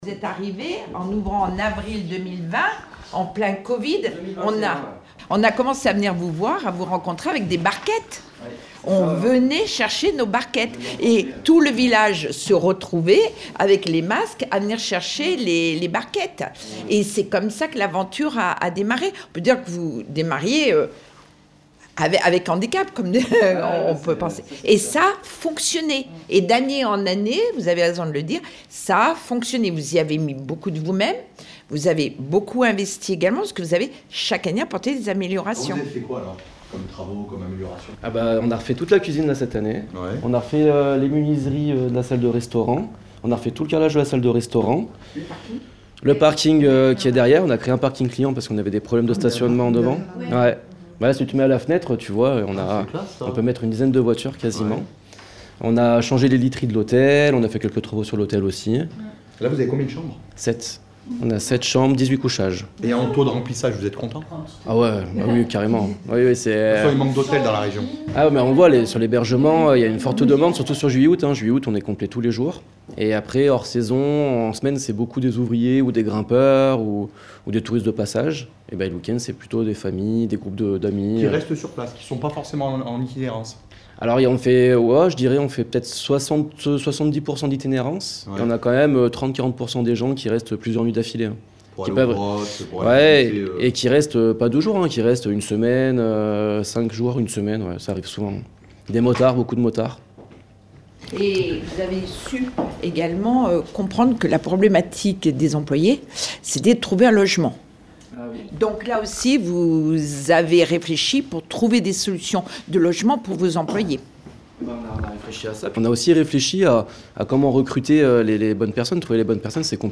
Rencontre lors d’une présentation à la presse